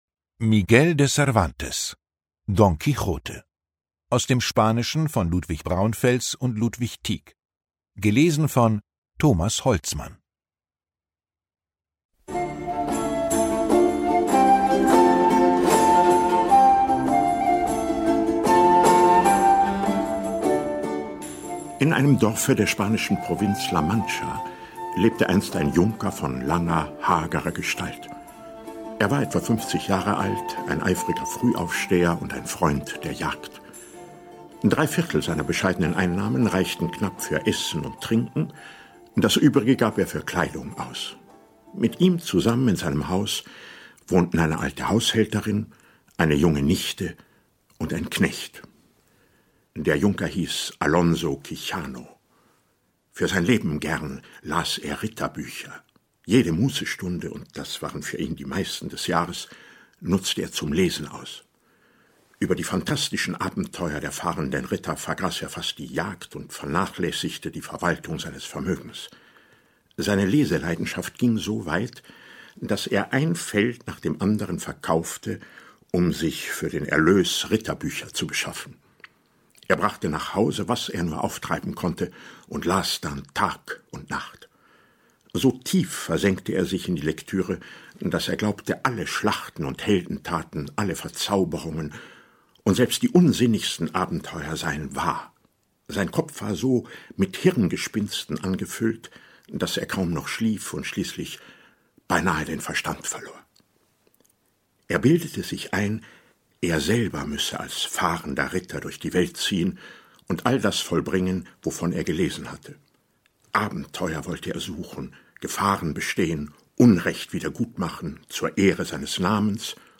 Lesung mit Thomas Holtzmann (1 mp3-CD)
Thomas Holtzmann (Sprecher)